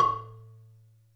Notice that during the first 100 milliseconds of vibration (1/10 second), there is a strong beating pattern. This is the result of the mixing of the two frequencies (1090 Hz and 1121 Hz). The resonators were removed from the marimba for this test to keep the fundamental low. Therefore, the sound linked below does not match the typical sound for an A2 bar with resonator in place.